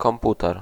Ääntäminen
IPA: /ɔʁ.di.na.tœʁ/